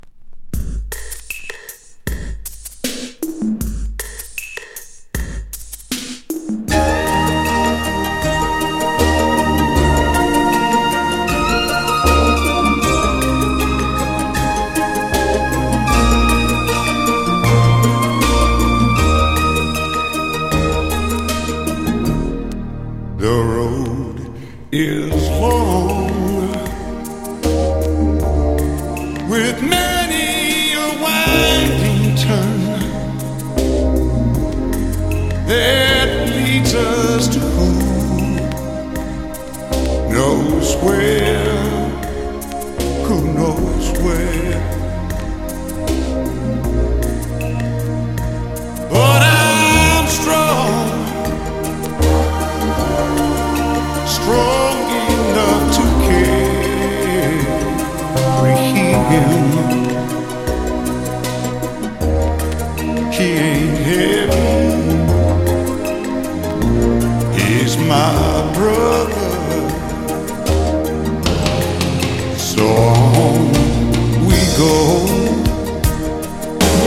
エレクトロ・バレアリック込み上げバラード